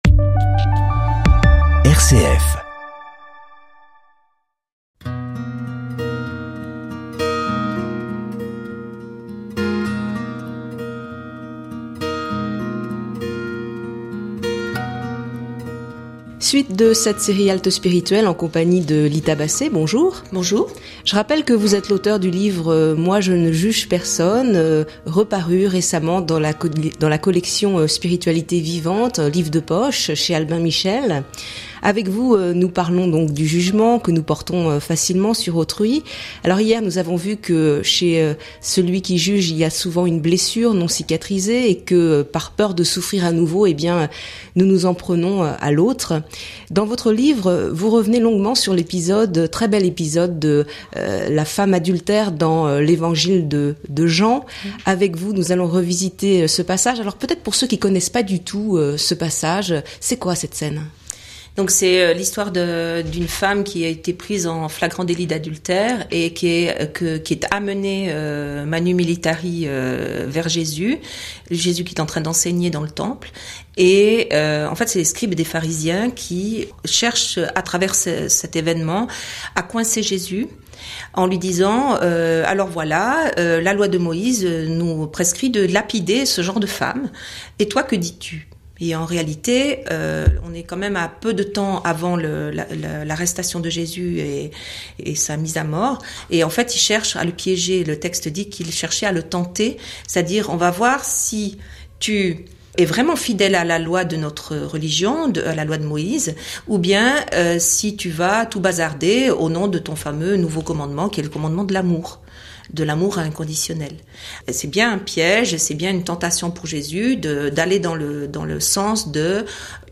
Courte halte pour engager une réflexion spirituelle profonde et accessible, autour d’une thématique d’actualité.